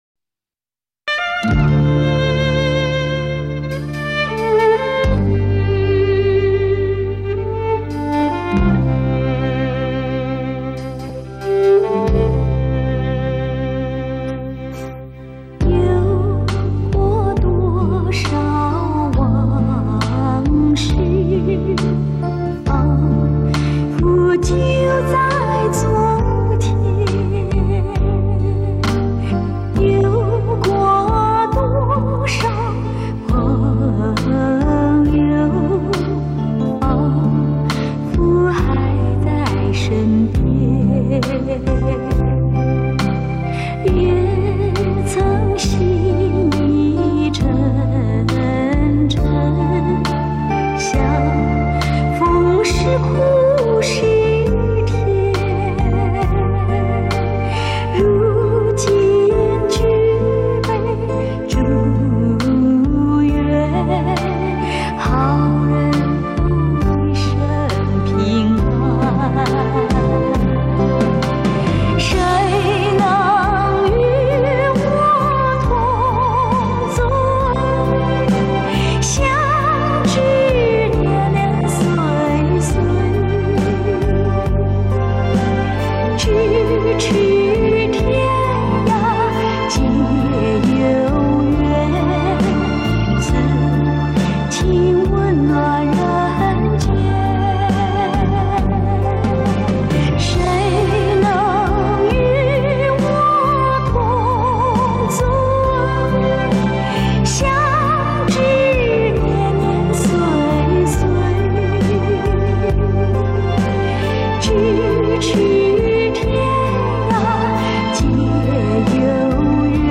唱彻云霄、让人久久不能忘怀的歌声，继续演绎中国激情。